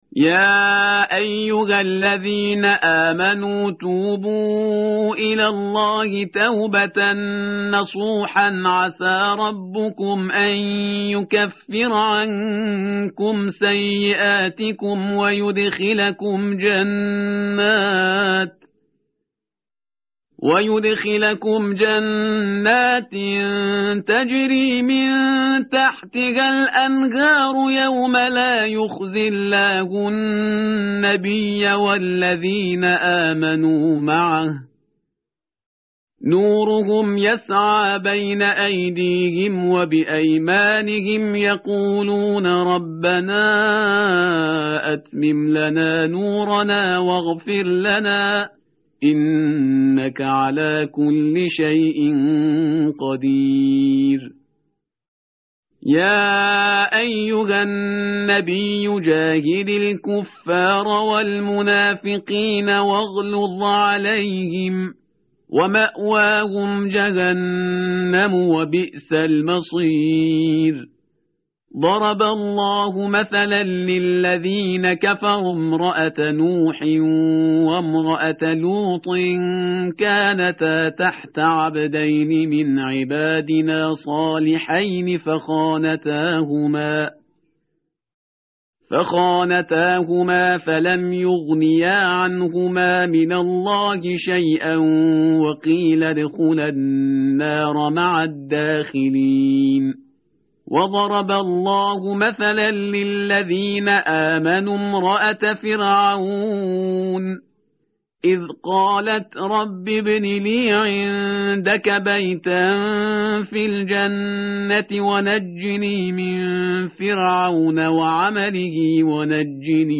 متن قرآن همراه باتلاوت قرآن و ترجمه
tartil_parhizgar_page_561.mp3